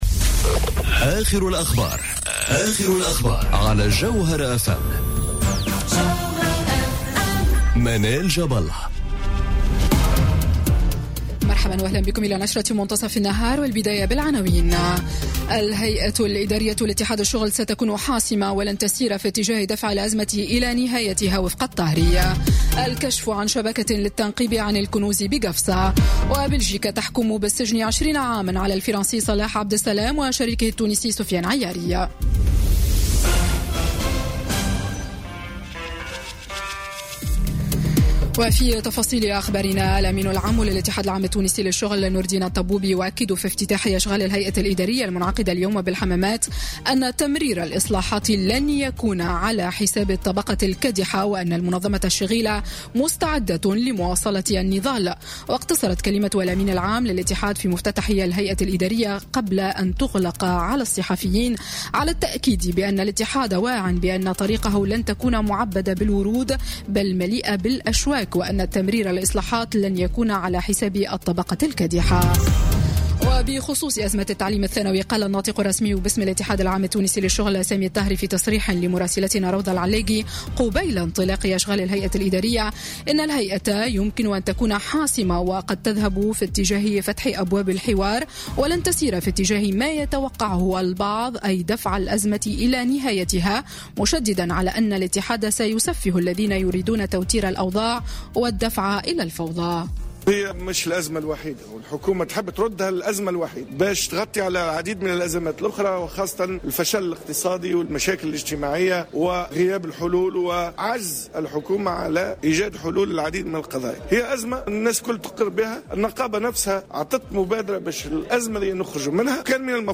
نشرة أخبار منتصف النهار ليوم الإثنين 23 أفريل 2018